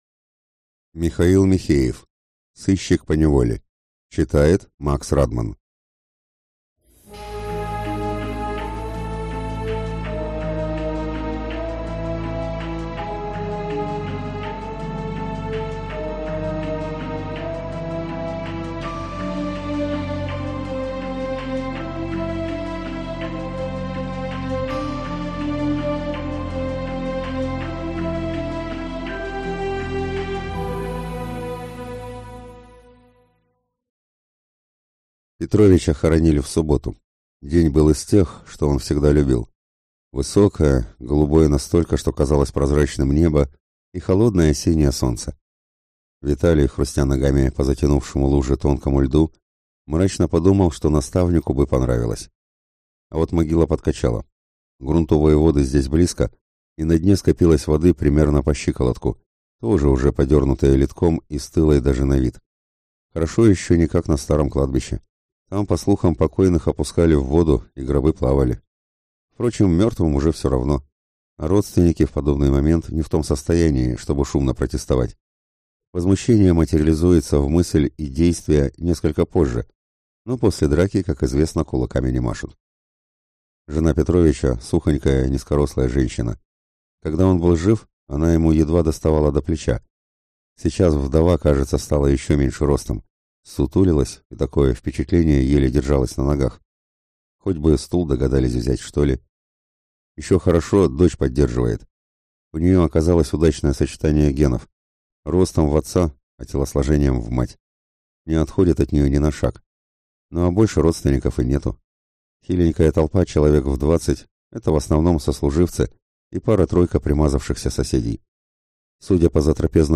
Аудиокнига Сыщик поневоле | Библиотека аудиокниг